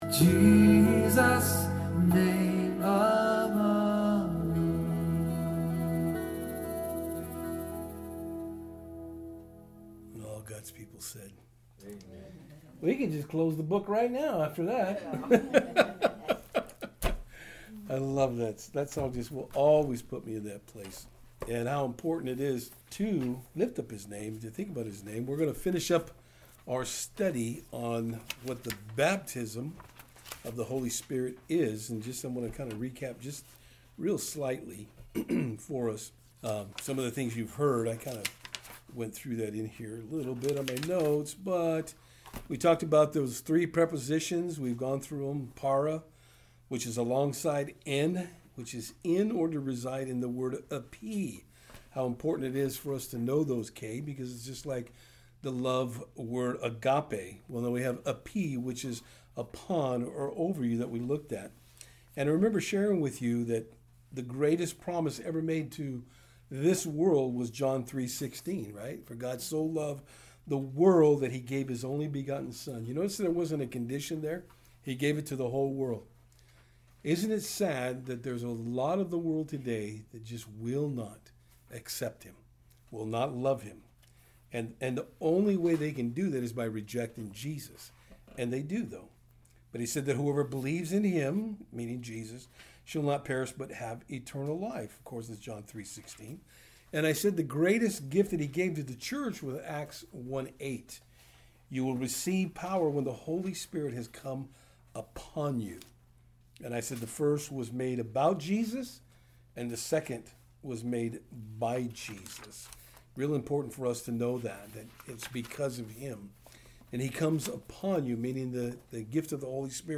Service Type: Thursday Afternoon